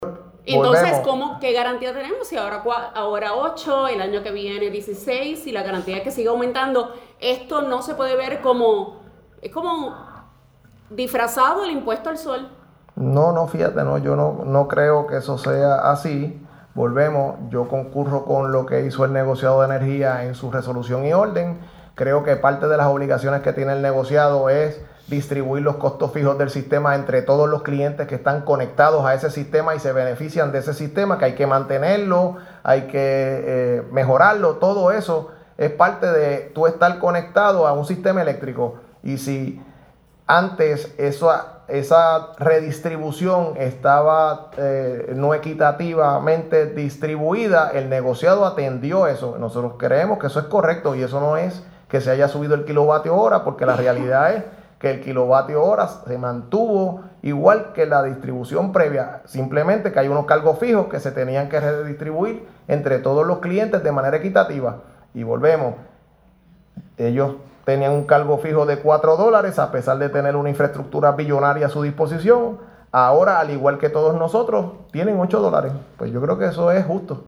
(16 de abril de 2026)- El Zar de Energía a preguntas de la prensa sobre si la decisión del NEPR de que si  era un aumento disfrazado, dijo: No, fíjate, yo no creo que eso sea así.